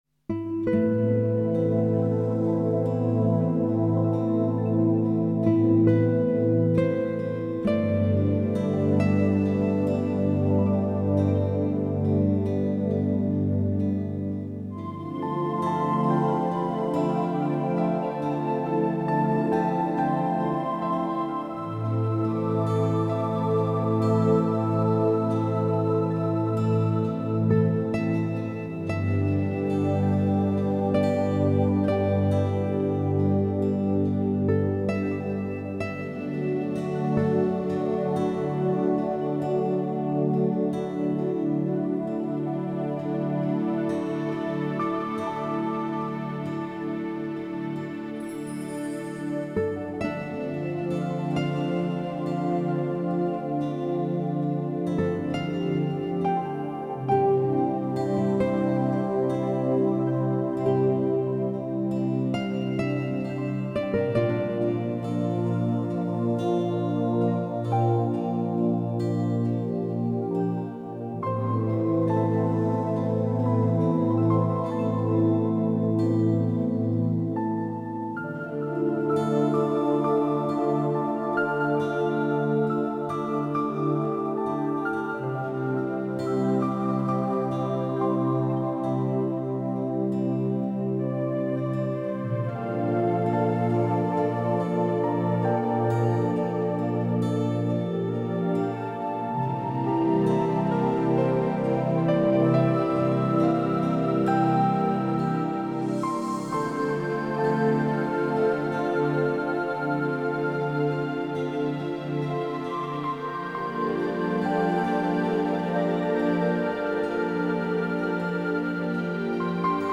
Znova hudba vhodná pre reiki, relaxáciu a meditáciu.